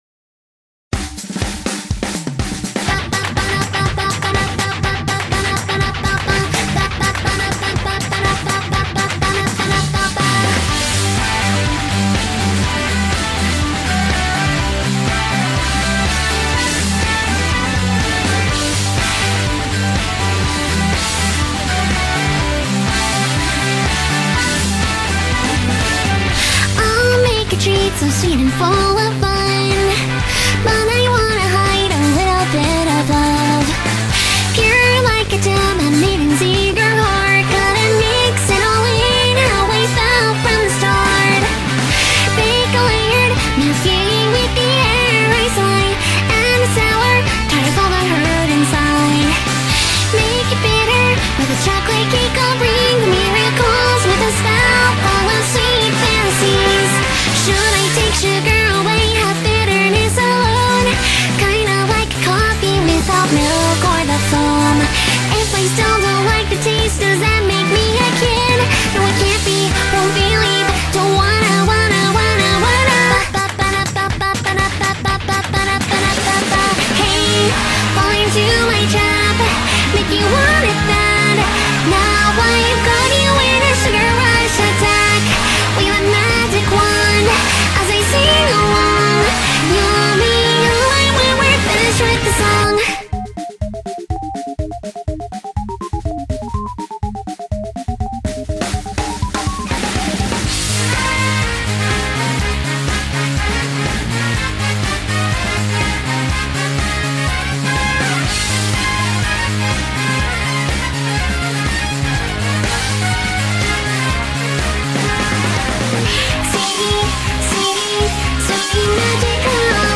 BPM61-123
Audio QualityCut From Video